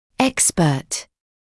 [‘ekspɜːt][‘экспёːт]эксперт, специалист; экспертный, квалифицированный